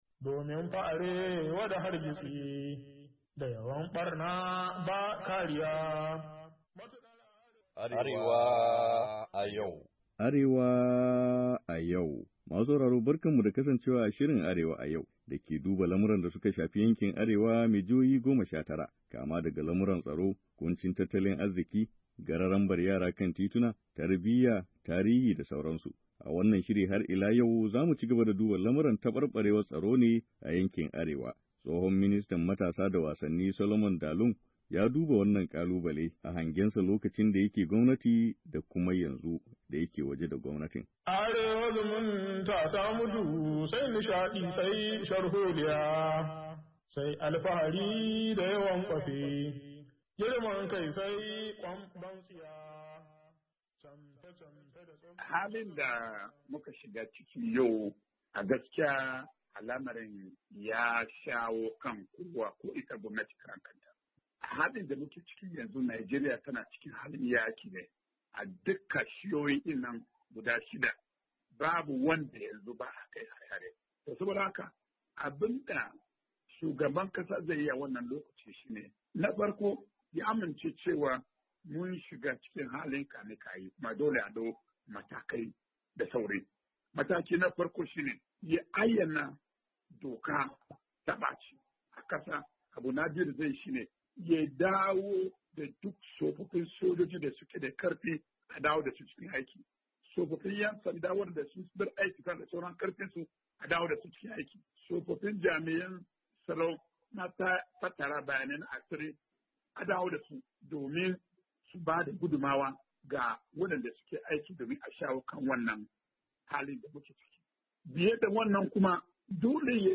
Shirin "Arewa A Yau" ya yi hira ne da tsohon ministan matasa da wasanni Barrister Solomon Dalung wanda ya auna yadda al'amuran tsaro suke a baya a arewacin Najeriya da kuma halin da yankin ya tsinci kansa a ciki.